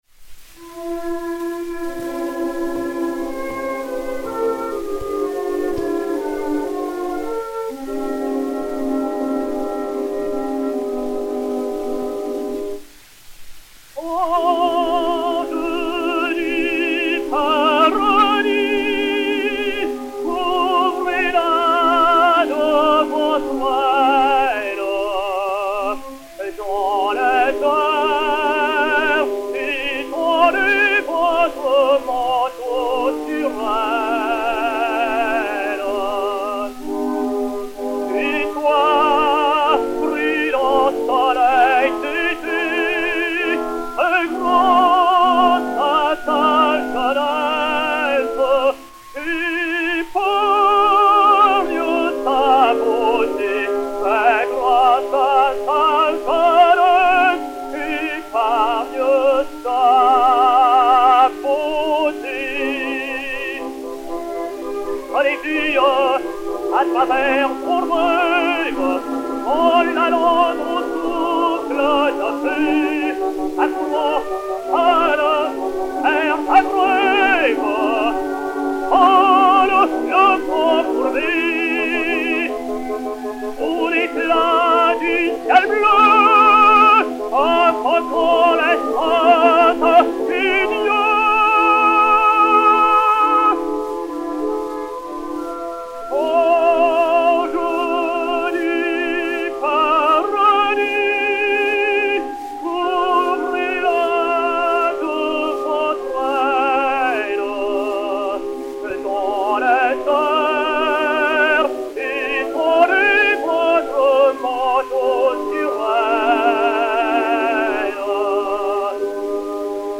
Duo "Vincenette a votre âge"
Ninon Vallin (Mireille), Léon Beyle (Vincent) et Orchestre